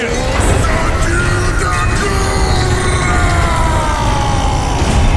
Play, download and share Vrykul battlecry original sound button!!!!
vrykul-battlecry.mp3